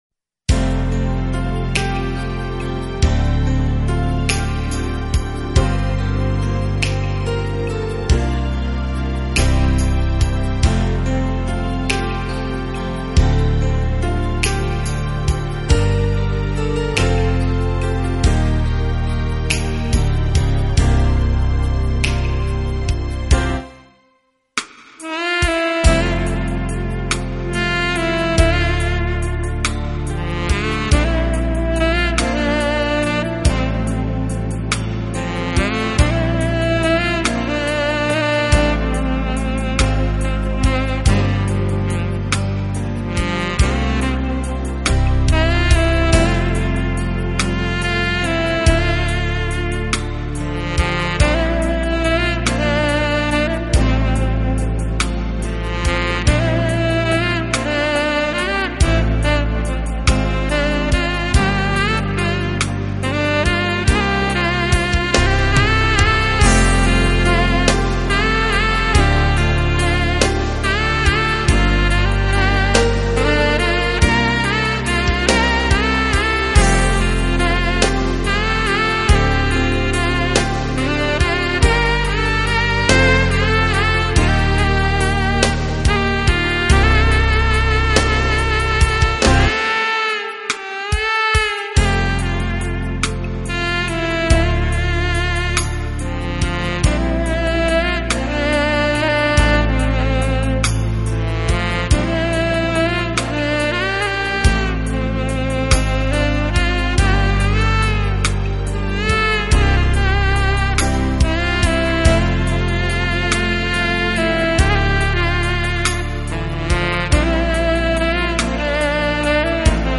有着现代Jazz萨克斯风的演奏风格，比